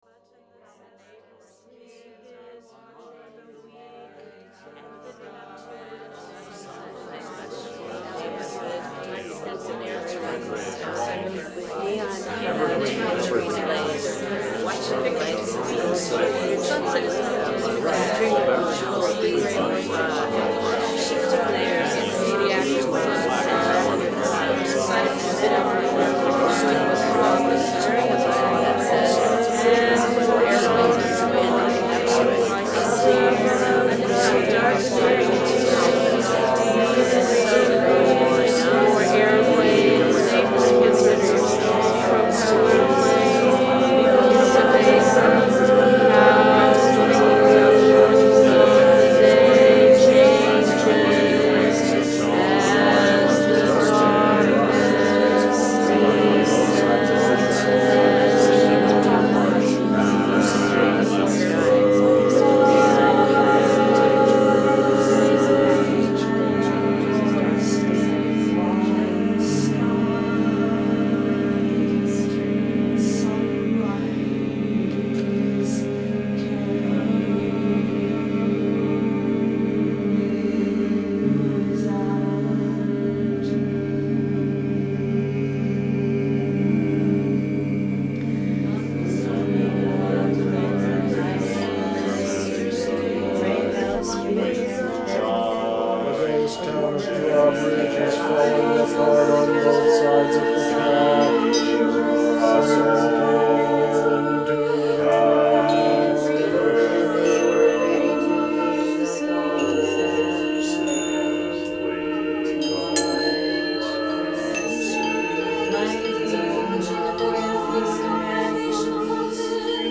(audio) the Cardew Choir as they rehearsed and performed a piece we have just begun to work on.
This is an excerpt (audio) from the work in progress for the choir.
ChoirMIX.mp3